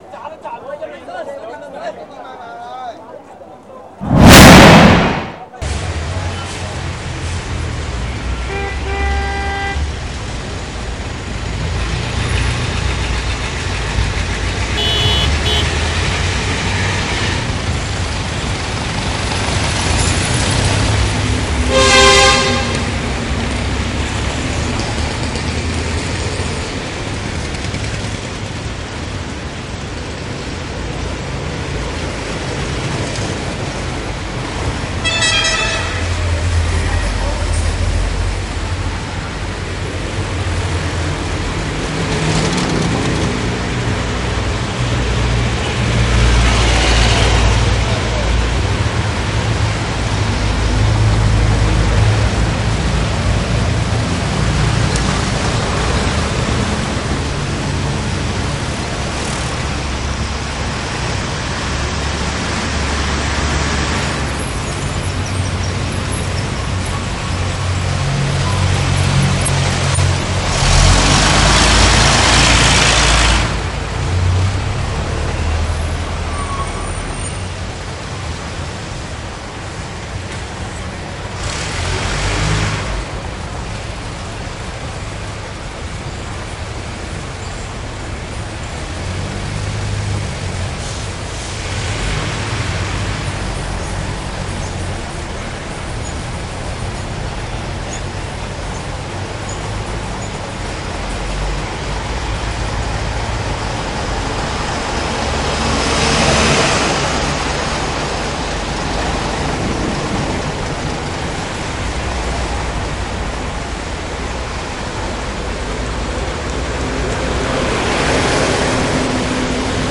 CrowdHongKong-NewYorkCity-Mix-1.mp3